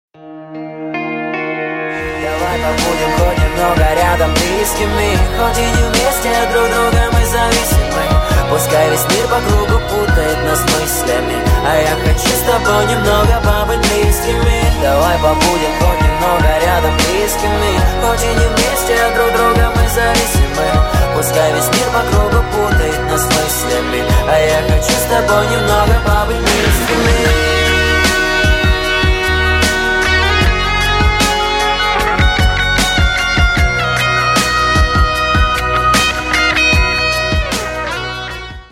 • Качество: 128, Stereo
романтичные